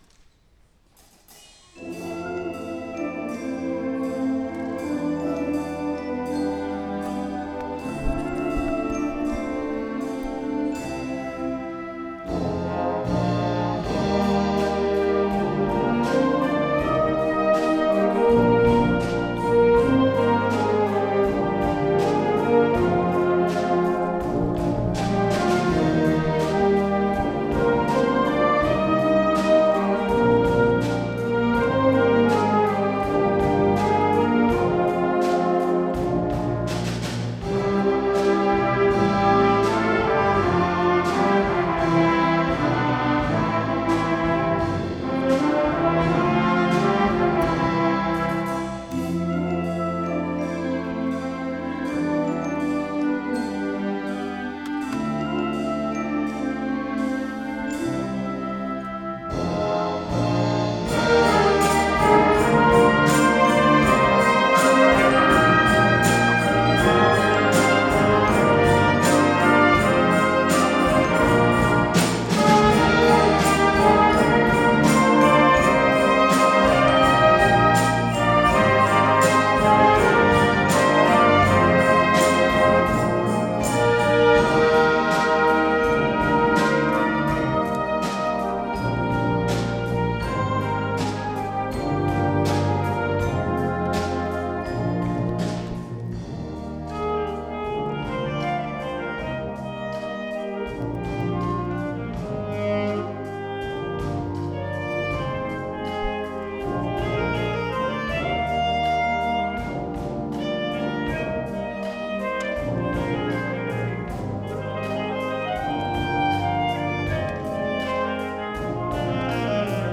Tｐ・Tbのソロにも挑戦しています。